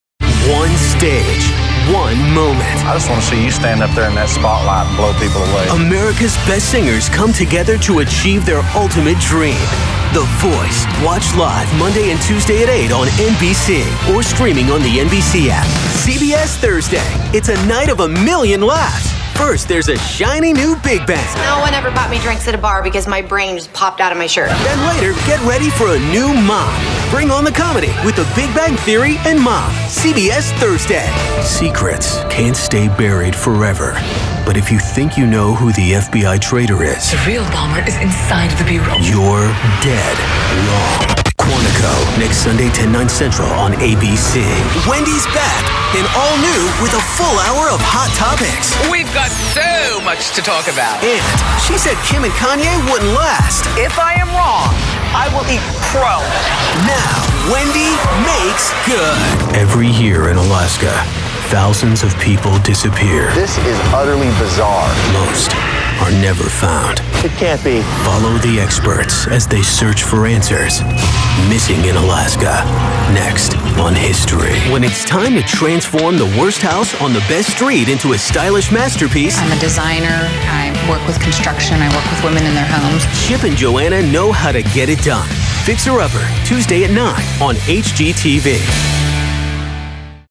Promo Demo